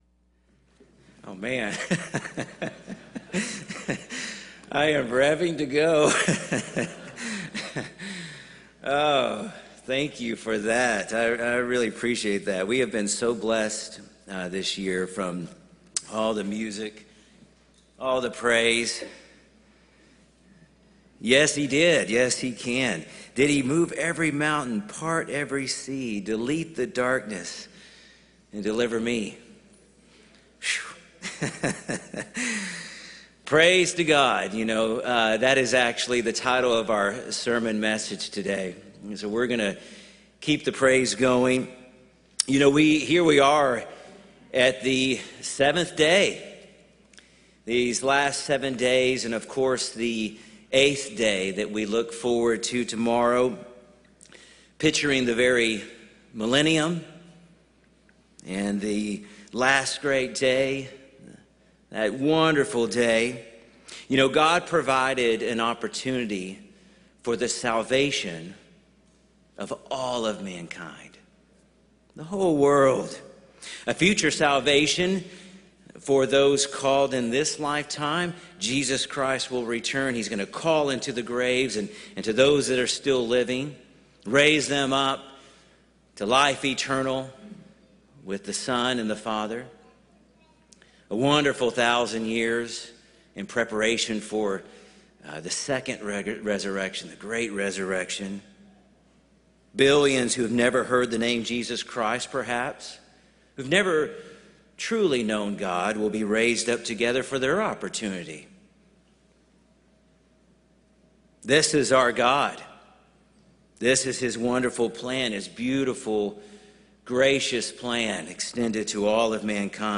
This sermon was given at the Lake Junaluska, North Carolina 2021 Feast site.